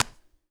Claps
Clap12.wav